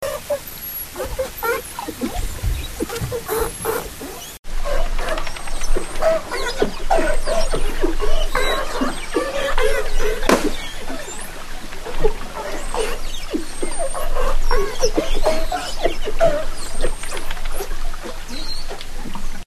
Cliquez sur les spectrogrammes pour écouter les enregistrements sonores sous-marins de grands dauphins collectés sur la côte ouest du Cotentin :
SONS PULSÉS EN RAFALE
Ces émissions sonores très particulières et variées sont entendues essentiellement lors des relations sociales fortes entre les individus. Elles font penser à des aboiements, des miaulements, des braiments, des meuglements, des gémissements, des grognements, des glougloutements, des pops, des couinements ou des grincements, etc.